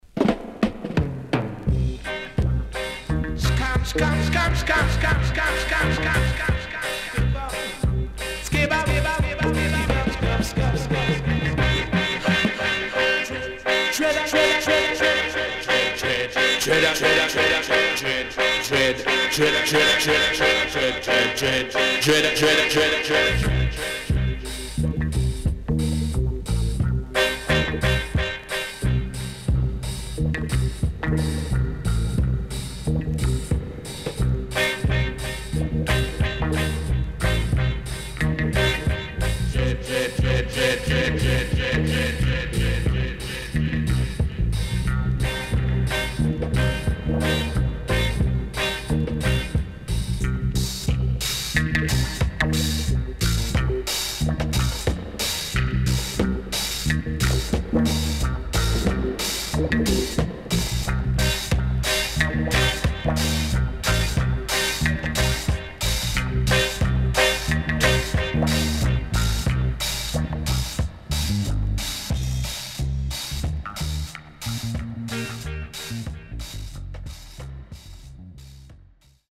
HOME > REGGAE / ROOTS  >  KILLER & DEEP  >  RECOMMEND 70's
SIDE A:軽くチリノイズ入ります。